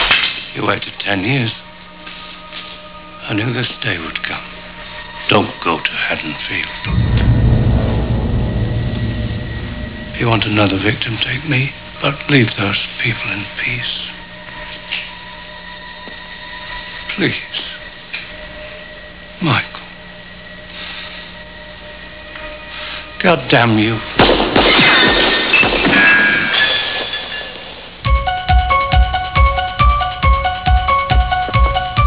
A clip of Loomis talking to Rachel.